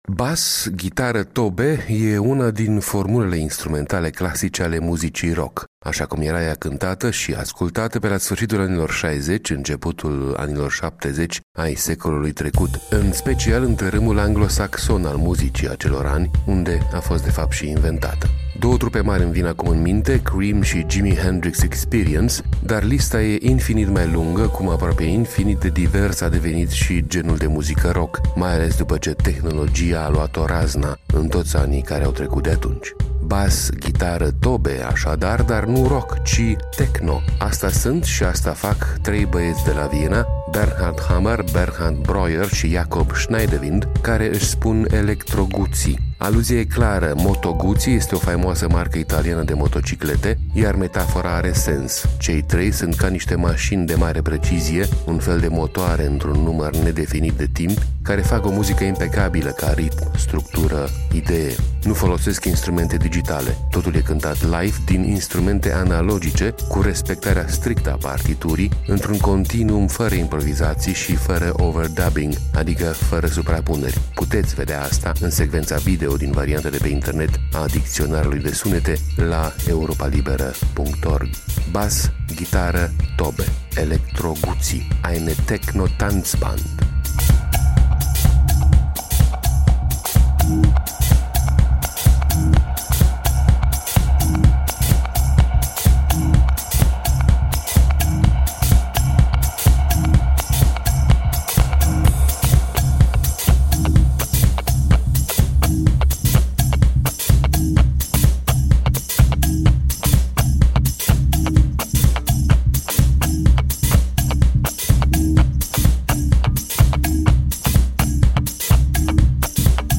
Motoare într-un număr nedefinit de timpi, care fac o muzică impecabilă ca ritm, structură, idee.